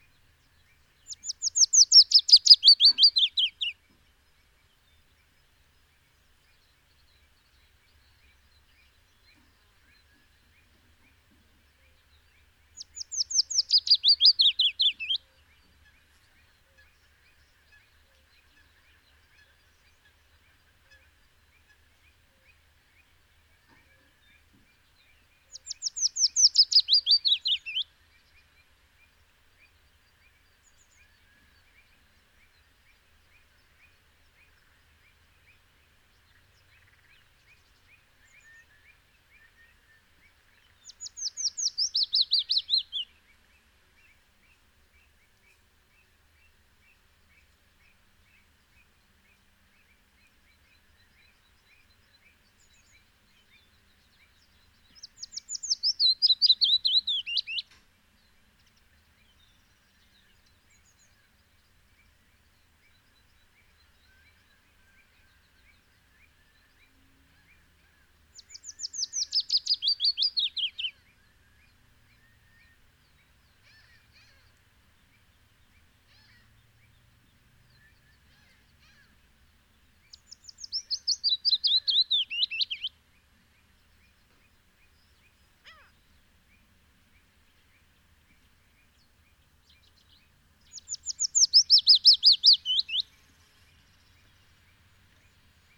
Son chant est constitué d’une strophe sifflante et plaintive répétée de façon assidue de type « fit fit fit sisisisi tutudoideda ».
Chant du Pouillot fitis